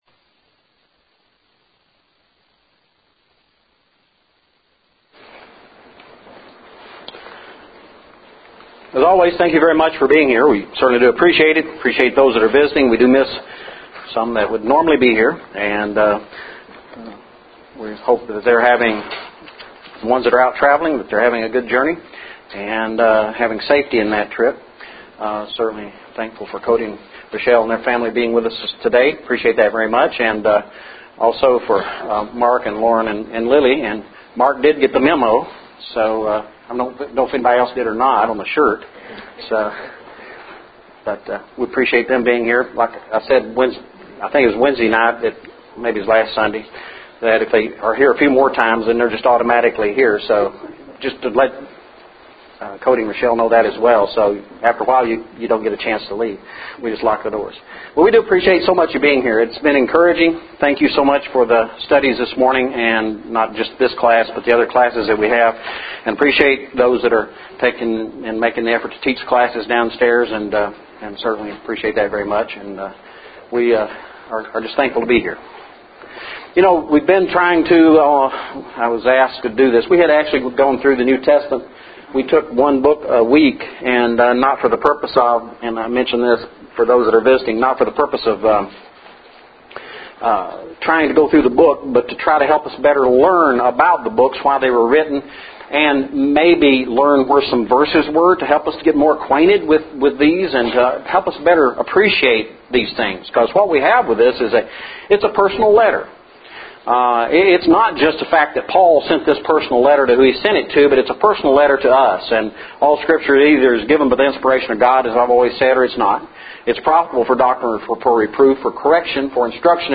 The Book of 2nd Corinthians Lesson – 04/01/12